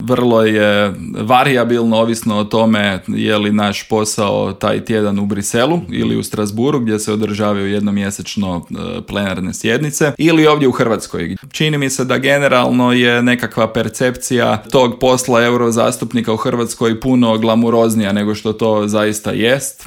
ZAGREB - "Europa je godinama zanemarivala pitanje obrane i sigurnosti i to se sada mijenja iz temelja jer naš odgovor ne može biti samo deklaratoran nego i konkretan", u Intervjuu Media servisa poručio je eurozastupnik iz redova HDZ-a Karlo Ressler.